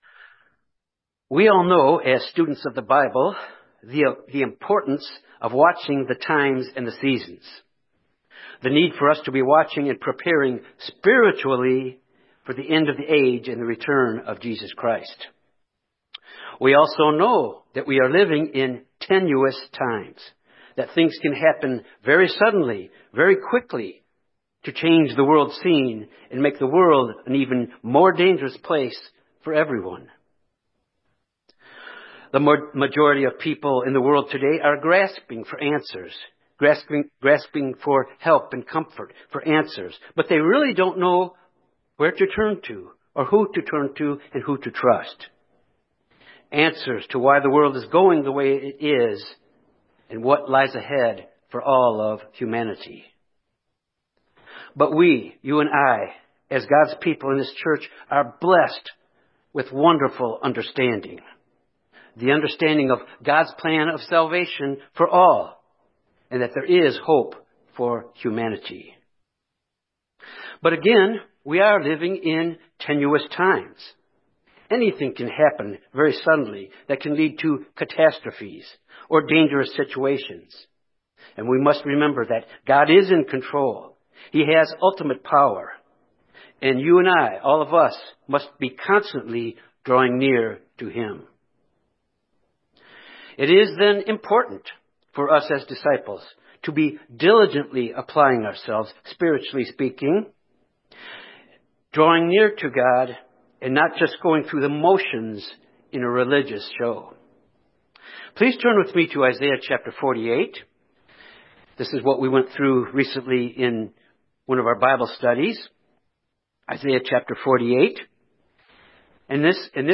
Sermons
Given in Little Rock, AR Jonesboro, AR Memphis, TN